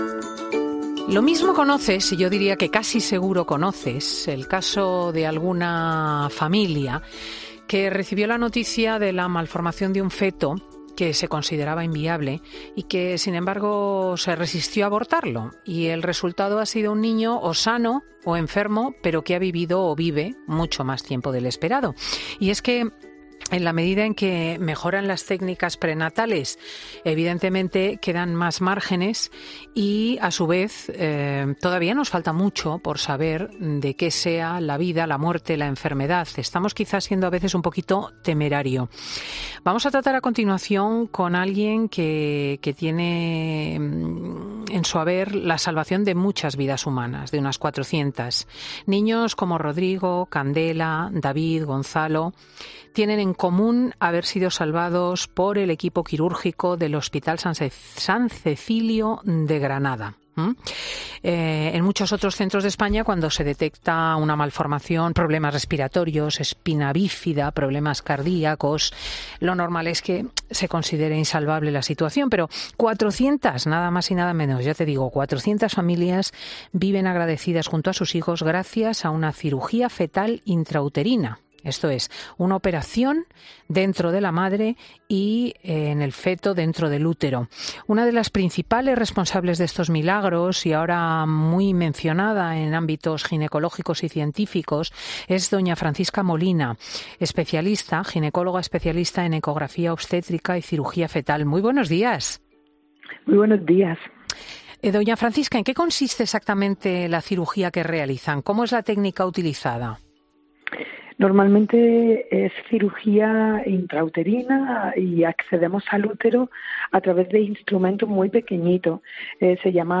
La experta ginecóloga relata en Fin de Semana los últimos logros de su equipo médico salvando vidas antes de que lleguen a nacer